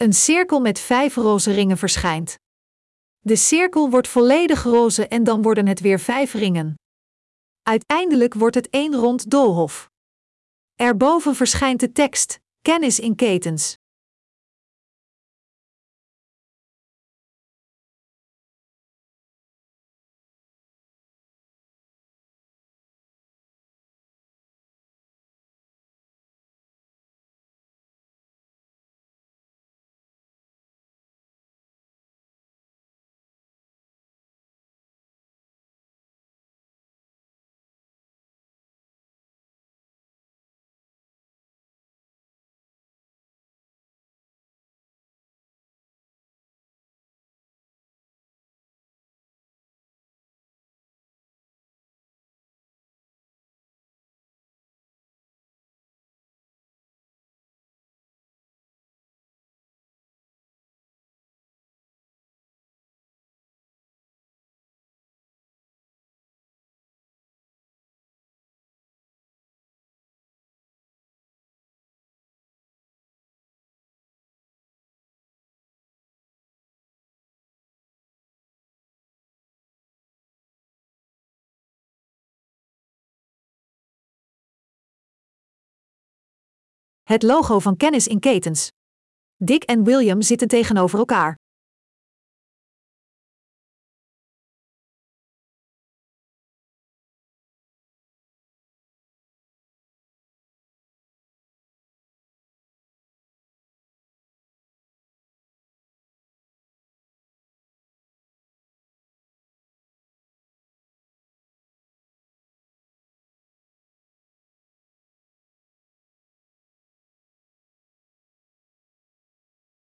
Ketentalkshow 'Kennis in ketens'